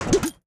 UIClick_Bubble Pop Metallic 01.wav